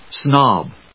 /snάb(米国英語), snˈɔb(英国英語)/